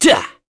Esker-Vox_Attack4.wav